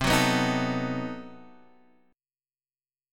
B7/C chord